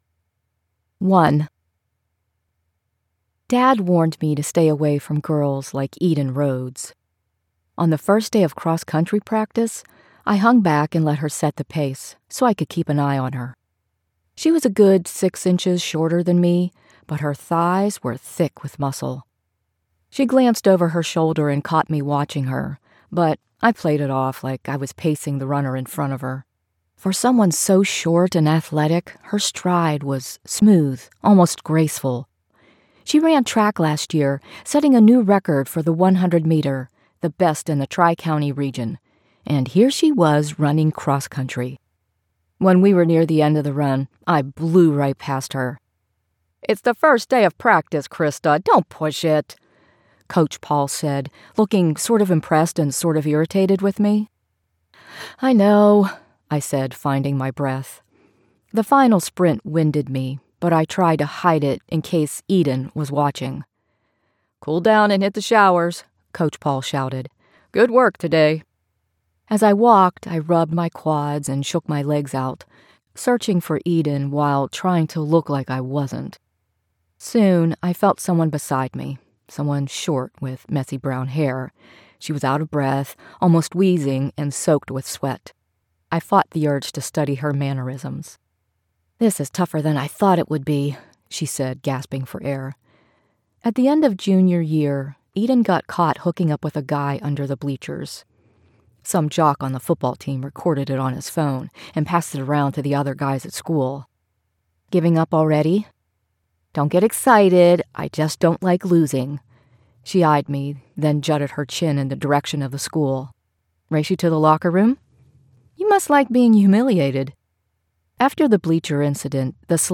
On the good days, I re-recorded my book. It wasn’t easy because I was sick and the inconsistent schedule drove me nuts, but in the end, I produced an audiobook with better audio quality and hopefully a better performance.